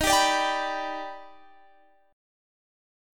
Eb+M7 Chord
Listen to Eb+M7 strummed